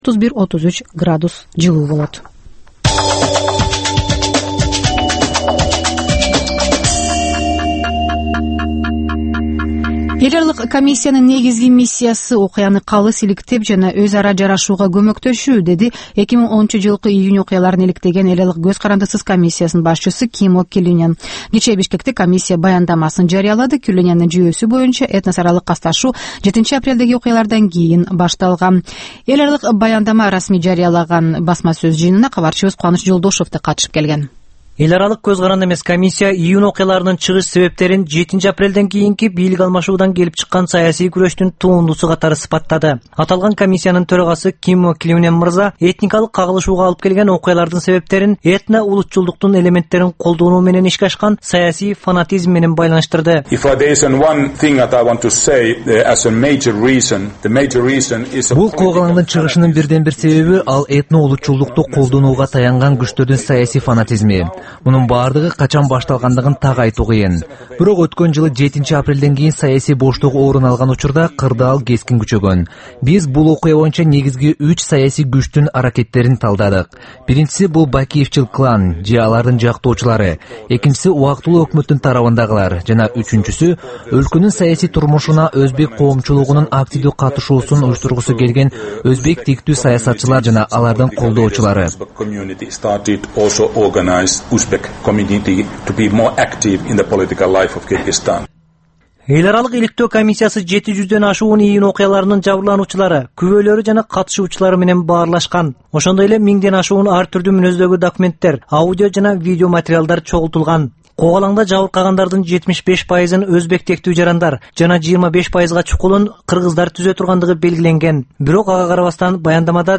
Бул таңкы үналгы берүү жергиликтүү жана эл аралык кабарлар, ар кыл орчун окуялар тууралуу репортаж, маек, талкуу, баян, күндөлүк басма сөзгө баяндама жана башка берүүлөрдөн турат. "Азаттык үналгысынын" бул таңкы берүүсү Бишкек убакыты боюнча саат 08:00ден 08:30га чейин обого чыгарылат.